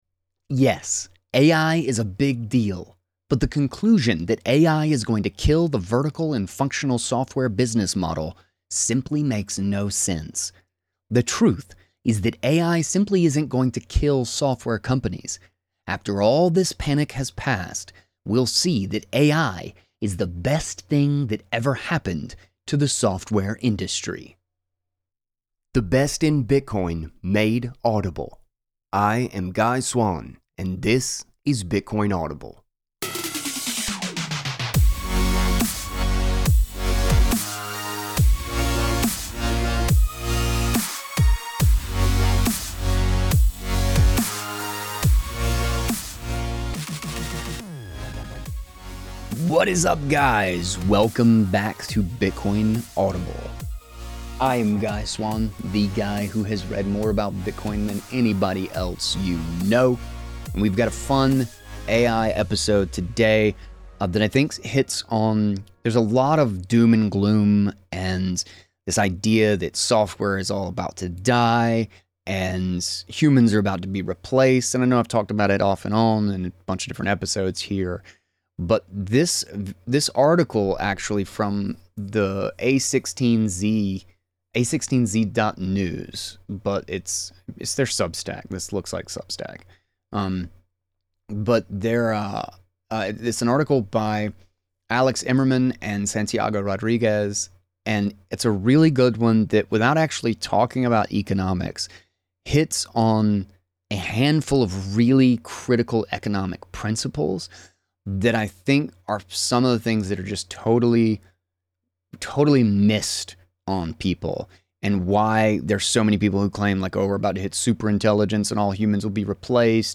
Listen in this episode where I read a A16Z article dismantling the doom and gloom, then rant on why AI amplifies human judgment, process power, and relative value - turning creative destruction into the ultimate boon for innovation.